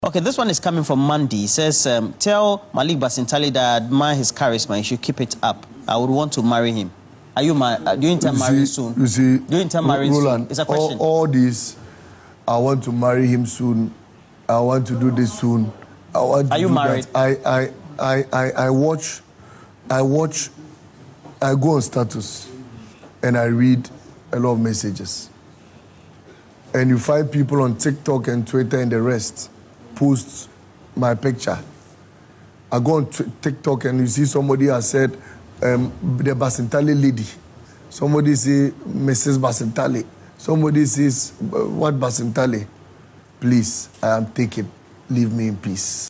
In an interview on Accra-based TV3